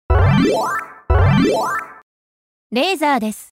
LS20の本体から音で警告します。警告の音は下記からご視聴ください。
レーザーアラーム機能：ボイスアラーム/ミュート
laser_14.mp3